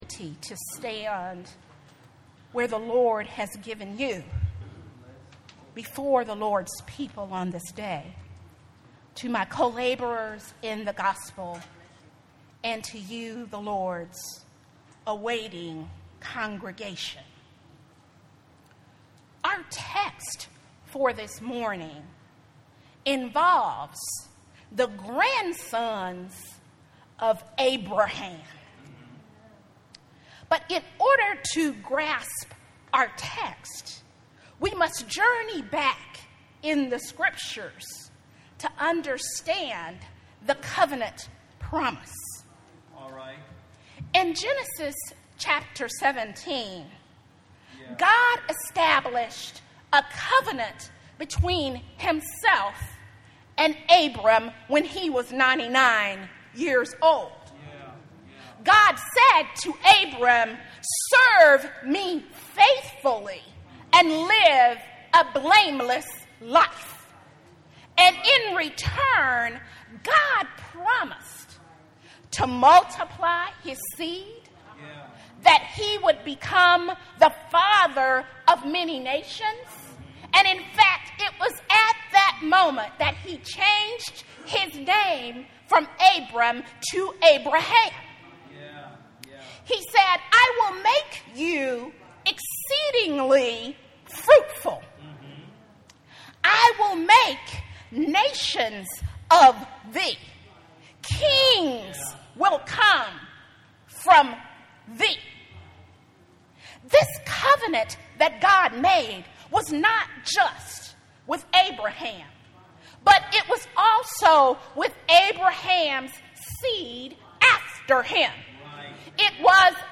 - Friendship Missionary Baptist Church